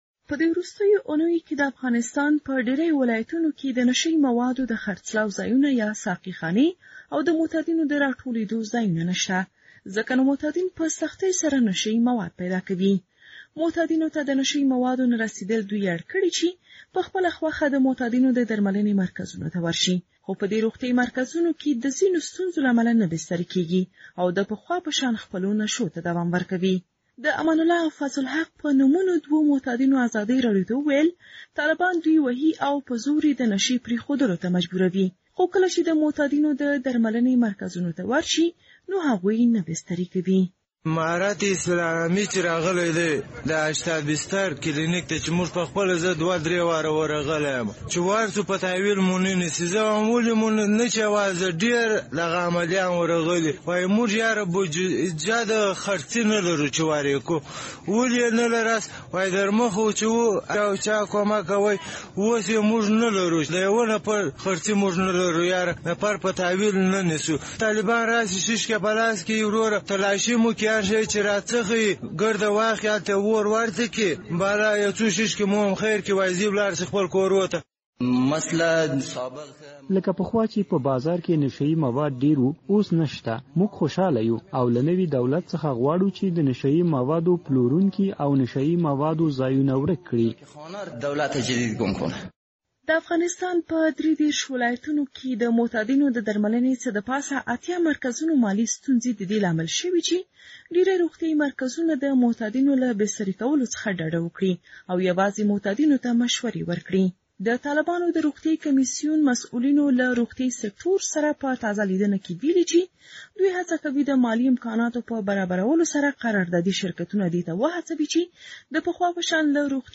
فیچر راپور